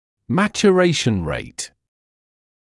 [ˌmæʧu’reɪʃn reɪt][ˌмэчу’рэйшн рэйт]скорость созревания